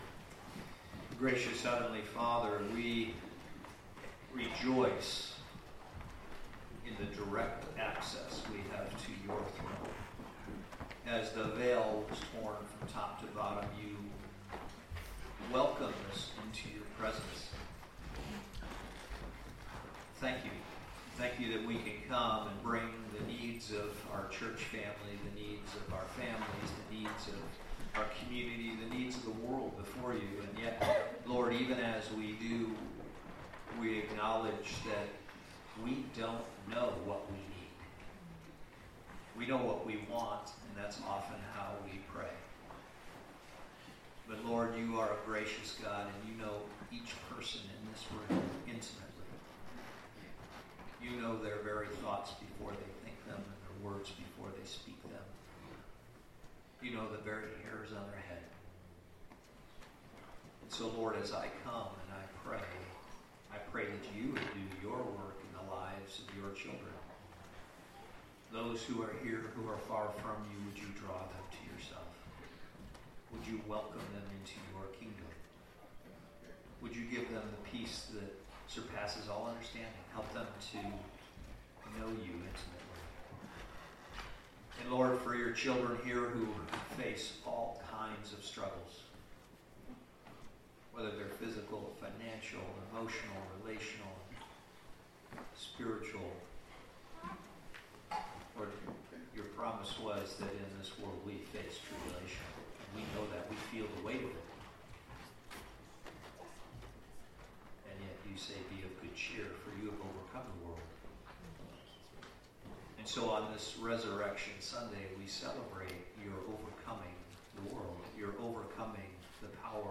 Easter Message